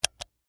Звуки нажатия кнопки
Звук клика кнопки джойстика PlayStation